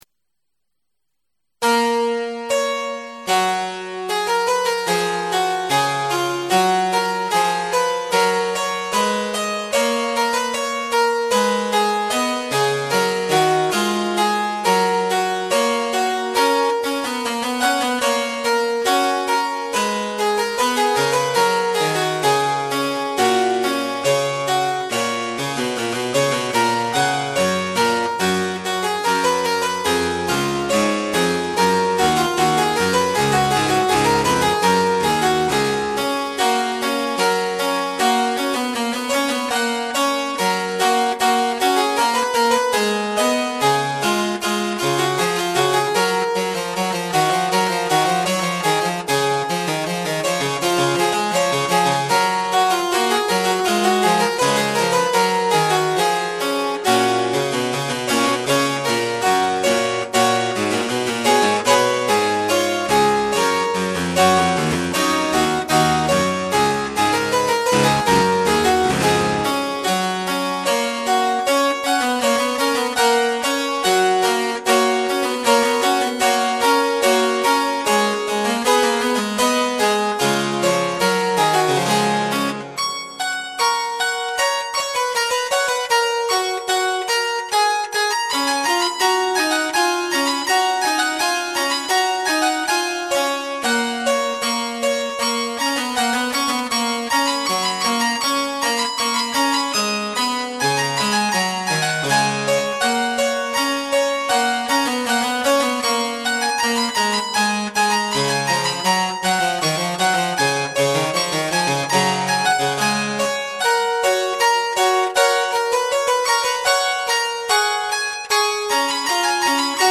Akustische Improvisationen (laufend ab ca. 1988)
Achtung: Auf der Grundlage teilweise sehr alter und mehrfach überspielter Kassettenaufnahmen
ist die Tonqualität nicht immer optimal.
Cembalosuite (Variables Arrangement aus zahllosen Mitschnitten 2006, Stand 12/06)
harp_moderato_fug.mp3